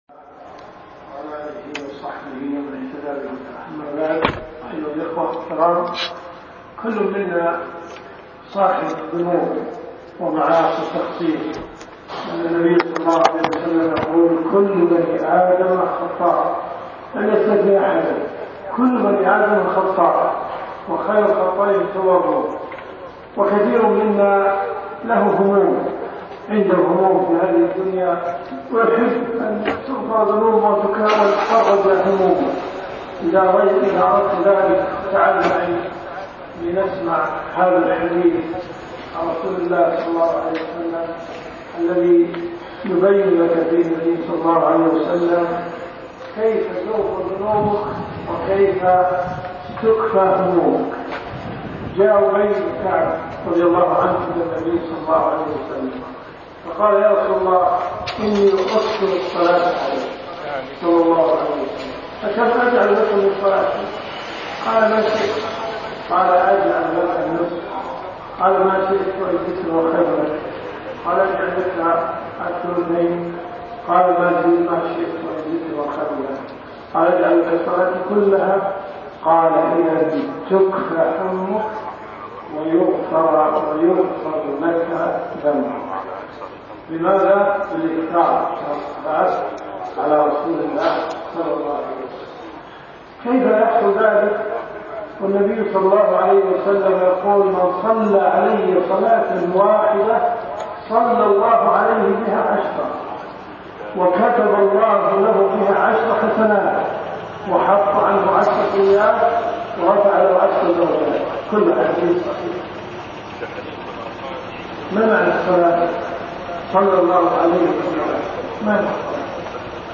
كلمات المساجد .
مسجد أبي ذر الغفاري رضي الله عنه